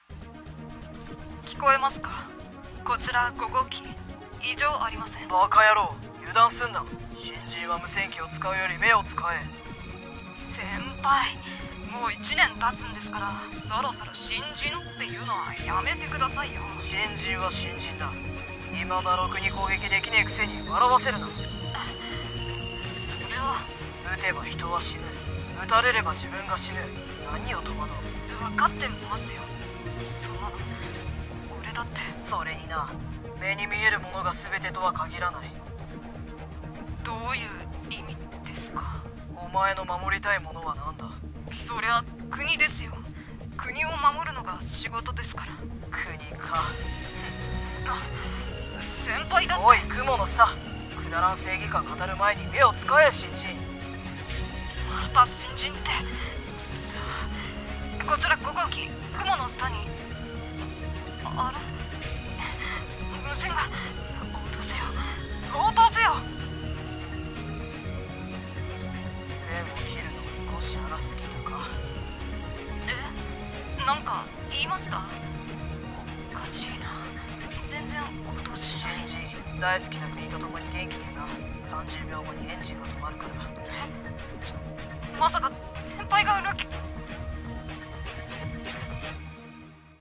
声劇 【 裏切り者 -traitor-】